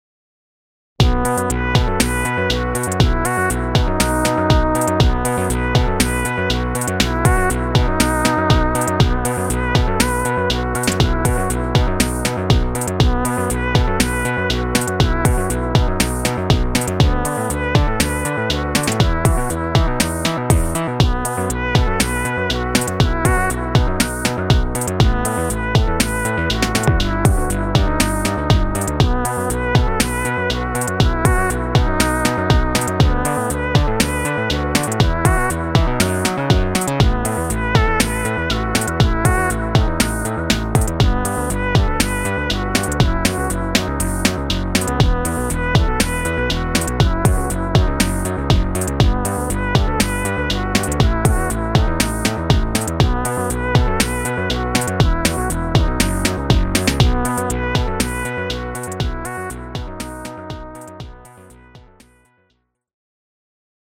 Main menu music